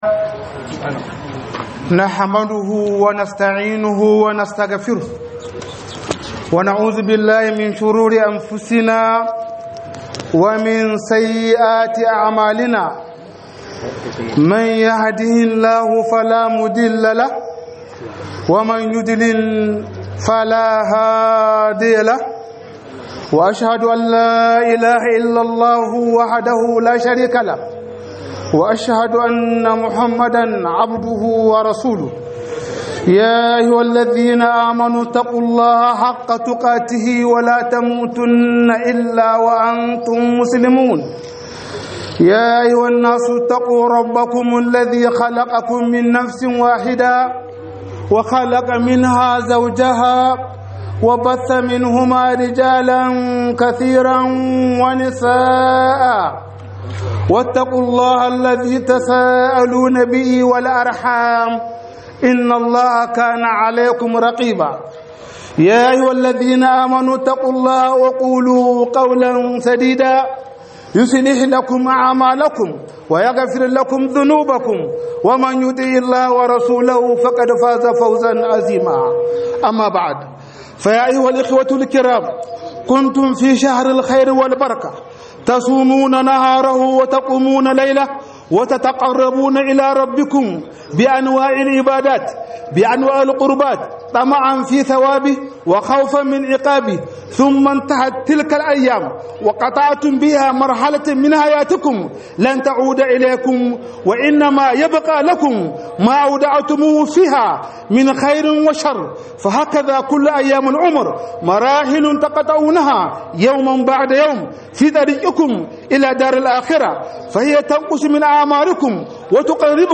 003 Kwanakin Dan Adam - HUDUBA